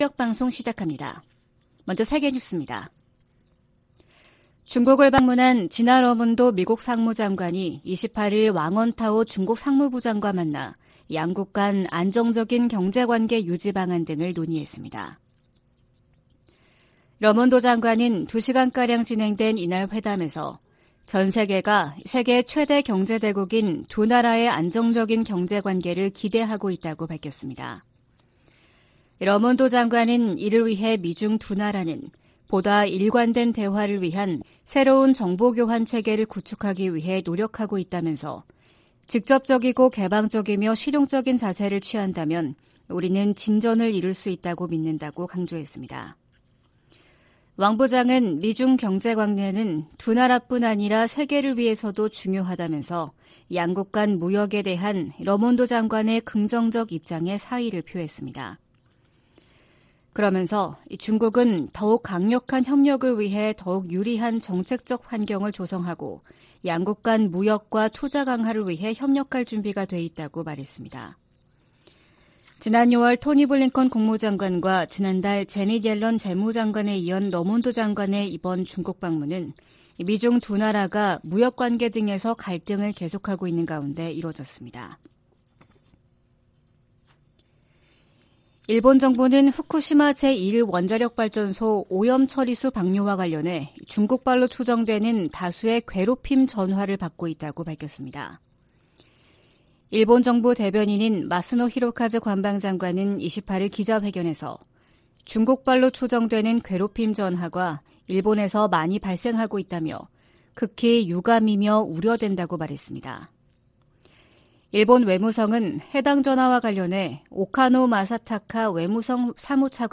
VOA 한국어 '출발 뉴스 쇼', 2023년 8월 29일 방송입니다. 유엔 안보리의 북한 정찰위성 발사 시도 대응 공개회의에서 미한일 등은 반복적으로 이뤄지는 도발을 규탄했습니다. 미 국무부가 후쿠시마 원전 오염처리수 방류 결정을 지지한다는 입장을 밝혔습니다. 북한이 신종 코로나바이러스 감염증 사태 이후 3년 7개월여만에 국경을 개방했습니다.